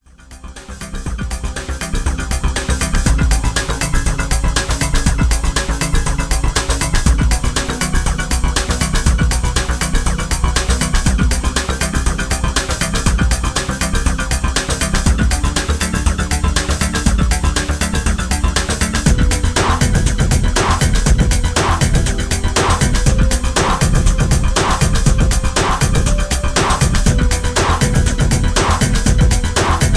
Background music suitable for TV/Film use.